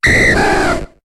Cri de Draco dans Pokémon HOME.